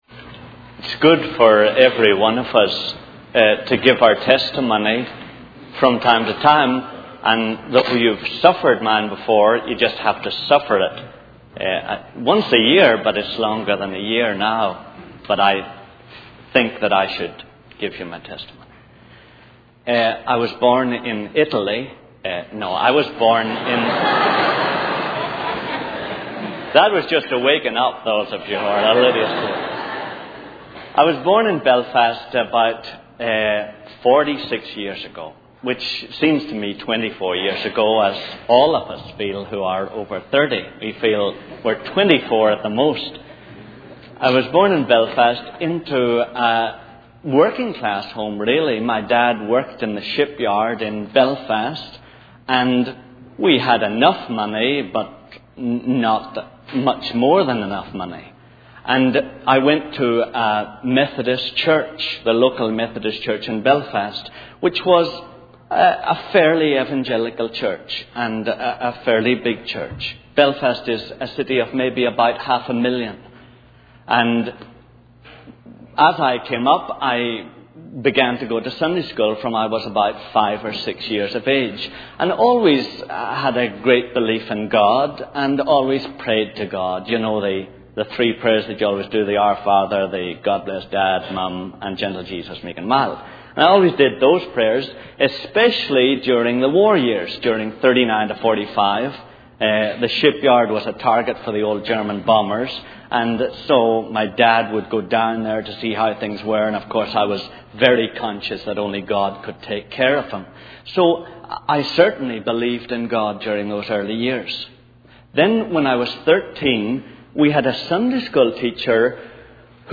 In this sermon, the speaker emphasizes the importance of hungering for the word of God and being fully committed to it. He shares his personal experience of struggling with sin and realizing the need for obedience to God. The speaker highlights the significance of believing in the Bible and understanding the consequences of sin.